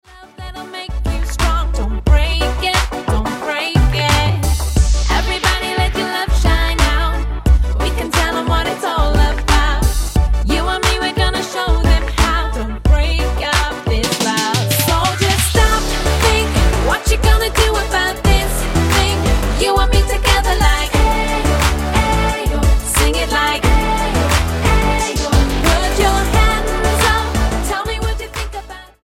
electro pop
Style: Pop